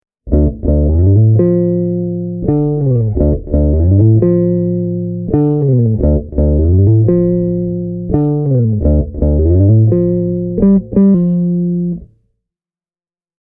Bassorekisteri on lämmin, keskialue siisti ja yläkerta silkkisen pehmeä.
Seuraavat esimerkit on äänitetty käyttämällä samanaikaisesti sekä kondensaattorimikrofonia kaiuttimen edessä että kombon suoraa XLR-lähtöä:
1985 Squier Jazz Bass